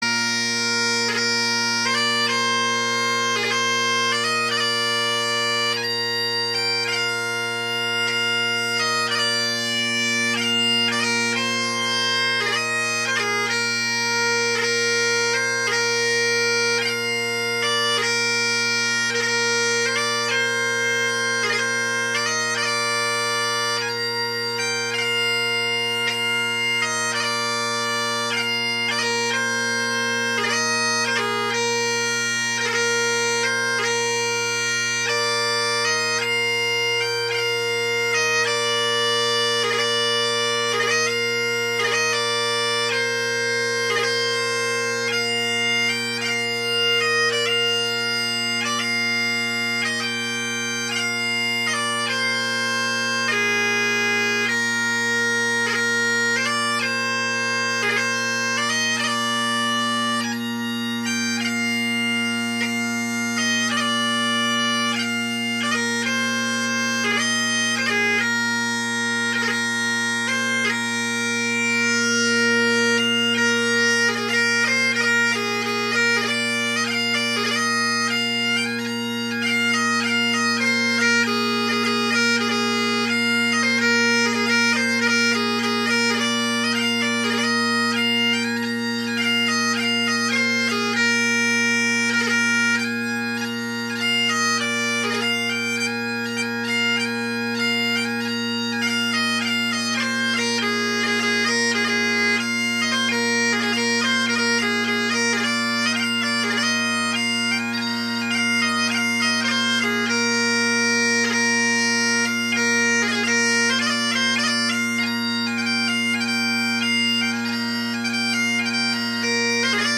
Bagpipe Sound Research, Great Highland Bagpipe Solo
Recordings taken from the end of my session with the Campbell Bb chanter with Terry pipes are below.
74th’s Farewell, Battle of Waterloo, 51st Highland Division (blowing out high A now) – better audio, darn fingers!